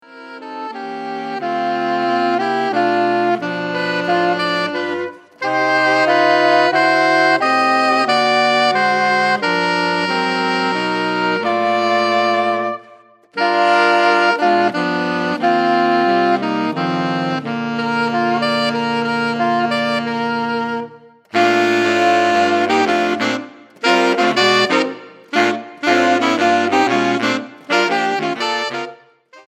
4 Saxophones (2ATB)